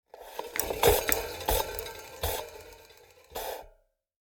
Rusty-bicycle-rotating-clicky-mechanism-2.mp3